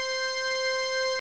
Organ02C.mp3